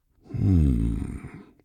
B_hmm4.ogg